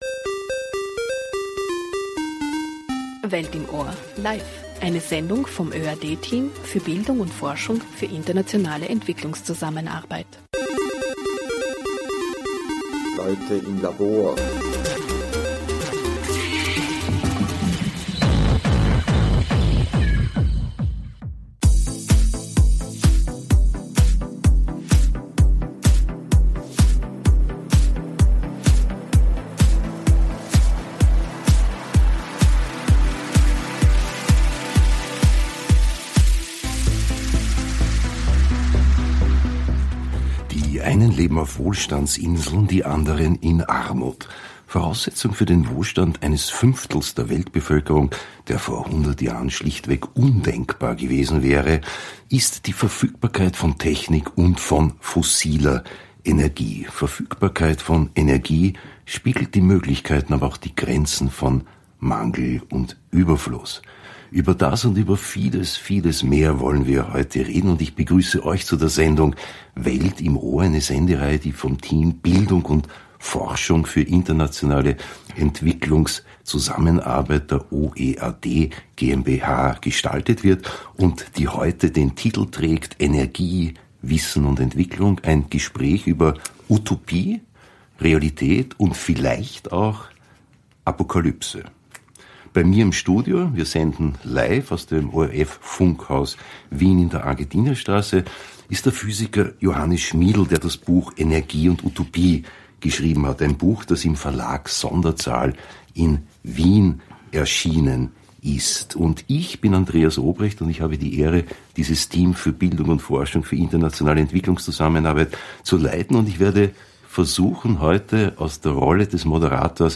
Energie, Wissen und Entwicklung – ein Gespräch über Utopie, Realität und vielleicht auch Apokalypse ~ Welt im Ohr Podcast